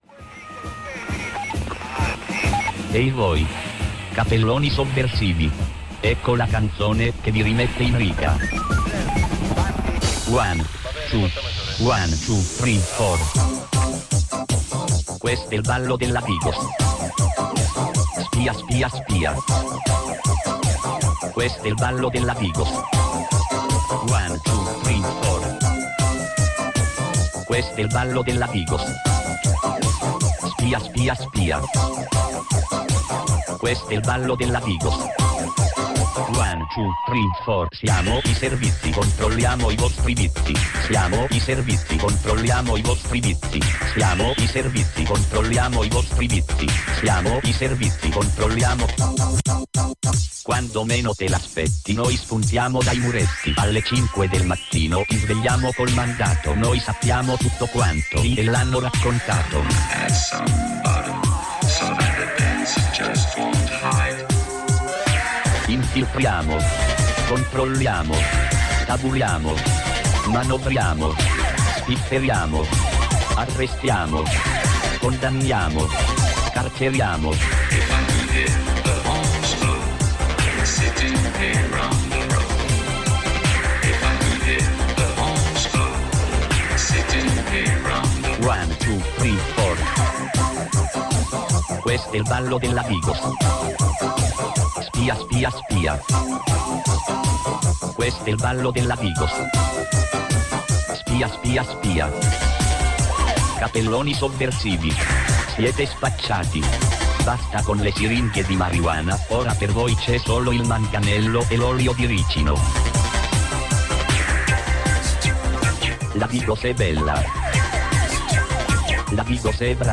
In studio oggi con noi